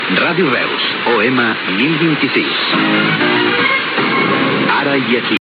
Identificació a la banda d'ona mitjana